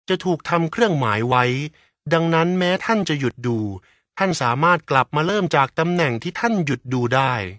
Professioneller thailändischer Sprecher für TV / Rundfunk / Industrie. Professionell voice over artist from Thailand.
Sprechprobe: Industrie (Muttersprache):